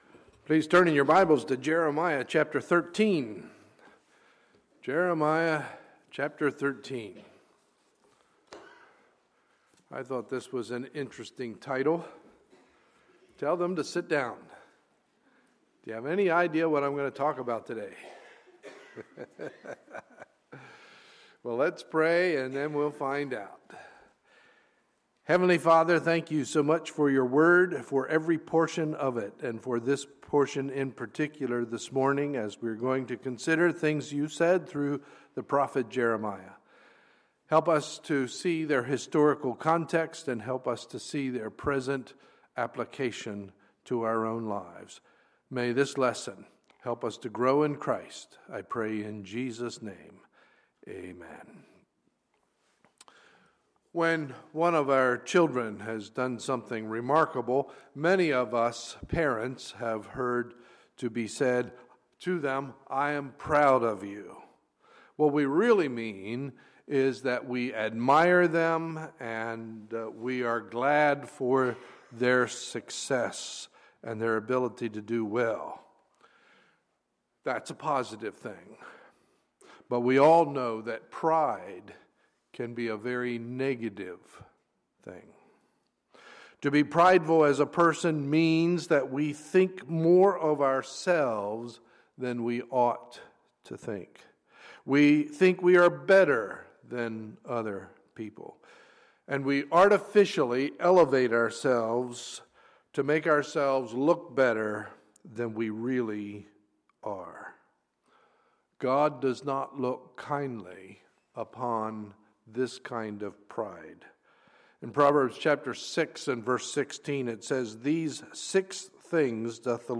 Sunday, April 19, 2015 – Sunday Morning Service